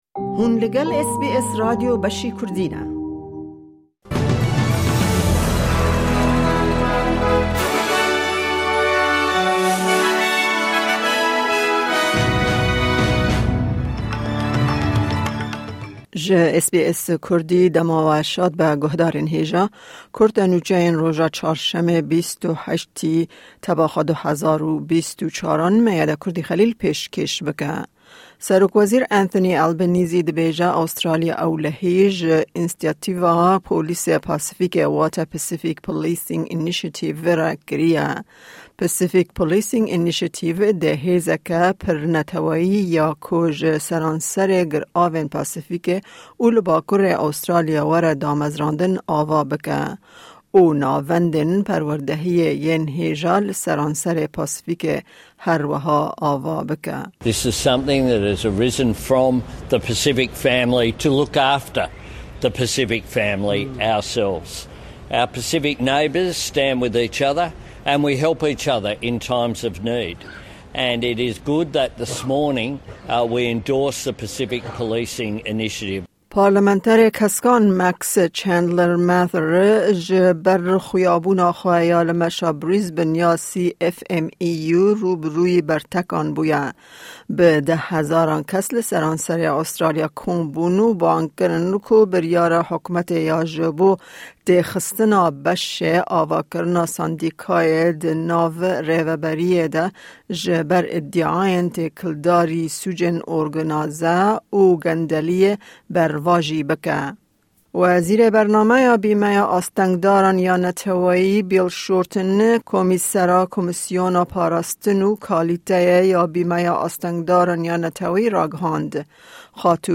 Kurte Nûçeyên roja Çarşemê 28î Tebaxa 2024